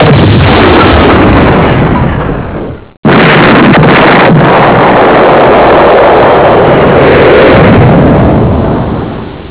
blast.wav